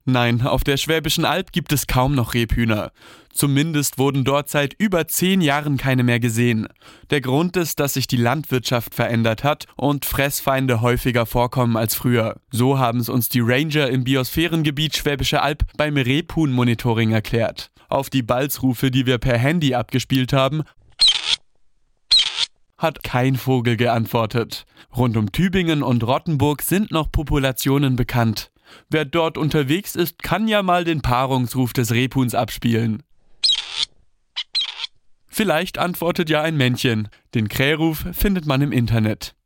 Balzrufe vom Handy abgespielt